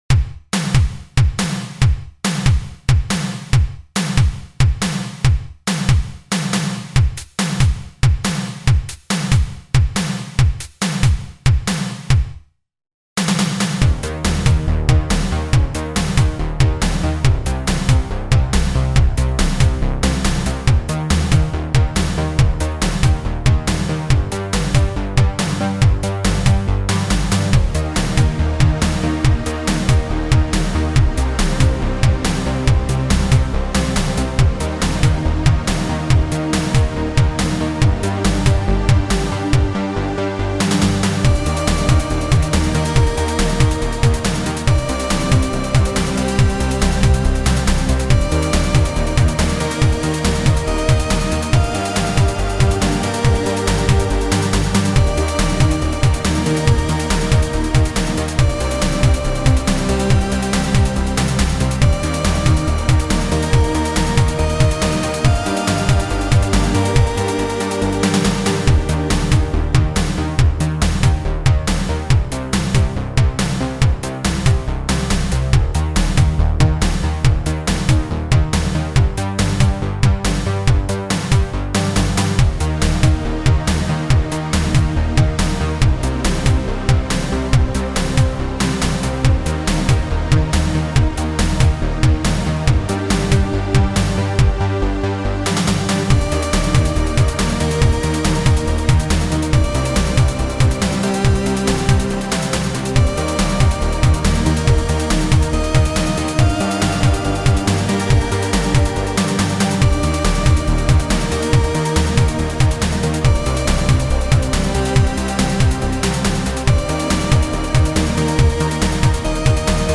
Pop Instrumental